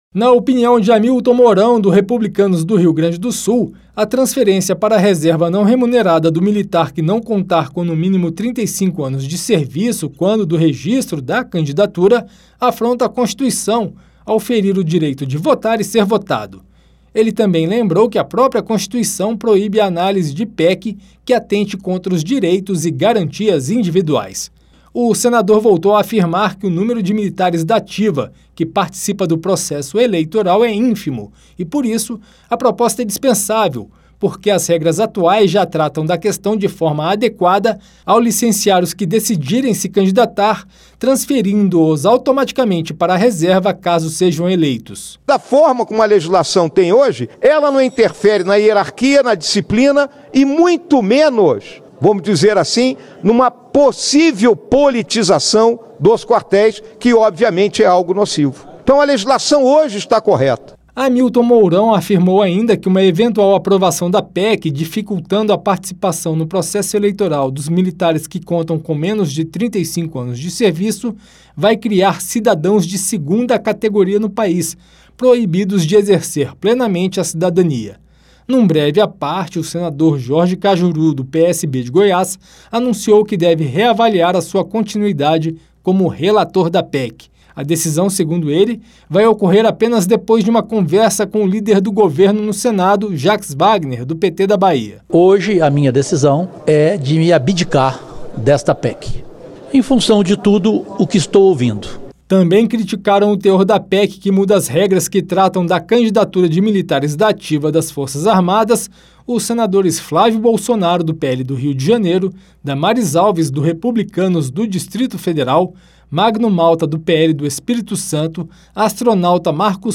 OS DETALHES NA REPORTAGEM